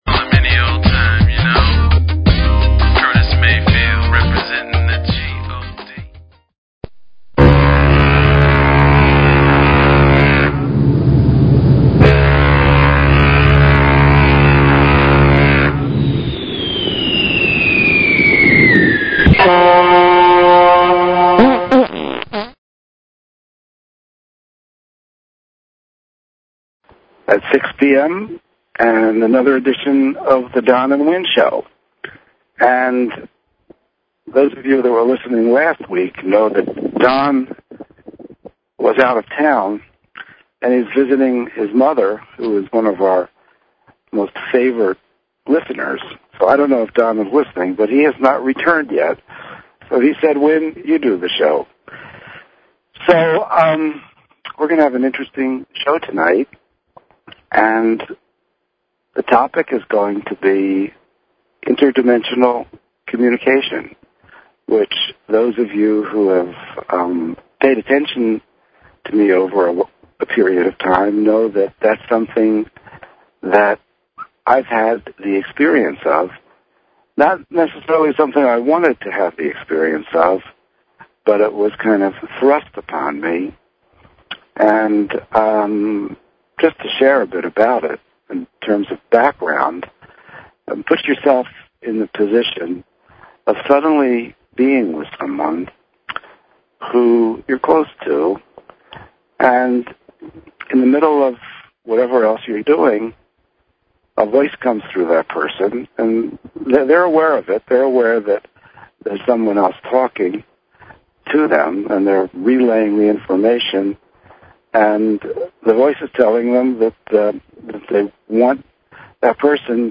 Talk Show Episode
Drive time radio with a metaphysical slant.